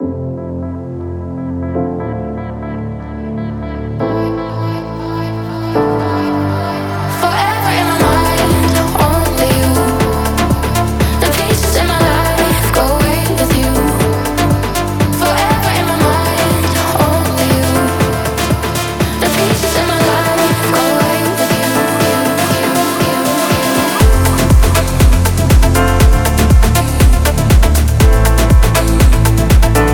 Жанр: Африканская музыка
# Afro House